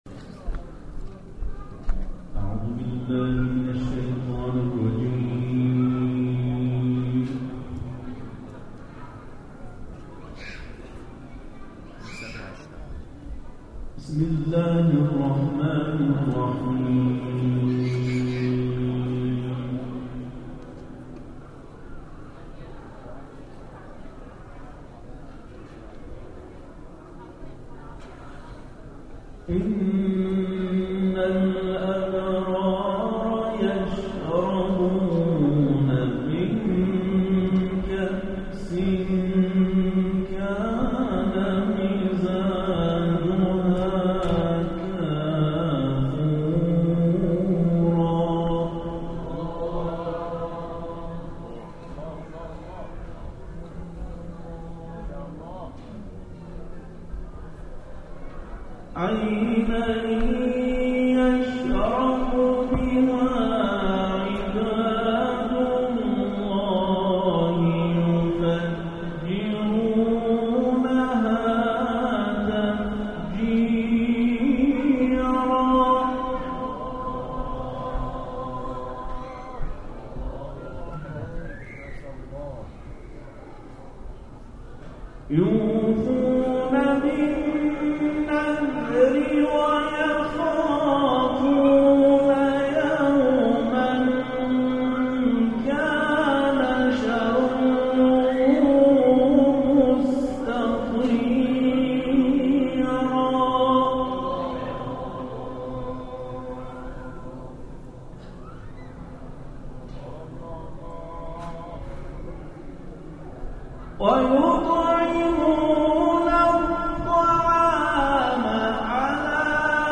تلاوت 3 قاری بین‌المللی در کنار مضجع شریف ثامن الحجج(ع) + صوت
به گزارش خبرگزاری بین‌المللی قرآن(ایکنا) به مشهد مقدس، دومین محفل قرآنی کاروان قرآنی ۸۰ نفری فرهنگسرای قرآن با حضور اساتید و قاریان این کاروان، در دارالقرآن حرم مطهر امام رضا(ع)، در جوار مضجع شریف سلطان طوس، از ساعت 19 الی 20:30 برگزار شد.
تلاوت